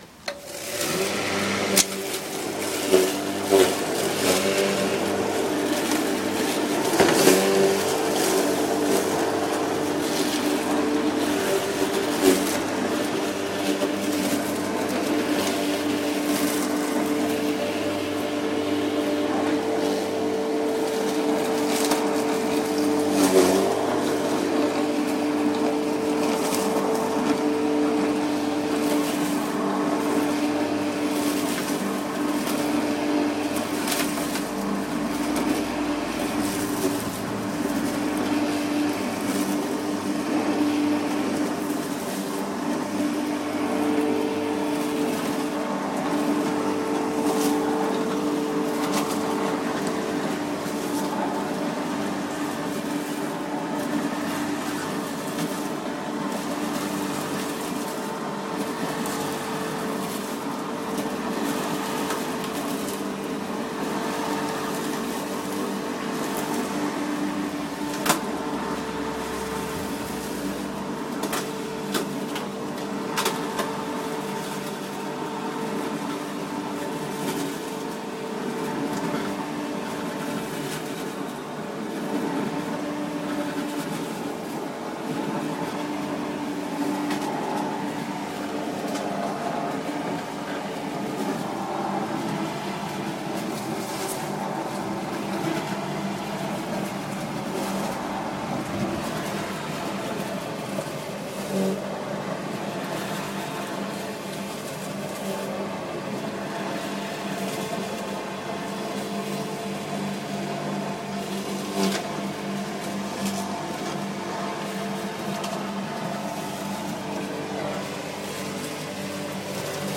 遥远而紧张的割草机
描述：远距离割草机应变和溅射。 1分钟，附近的第二台割草机启动。周末美国郊区的常见声音。
Tag: 汽油 割草机 溅射